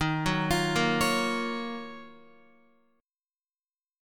D#m9 chord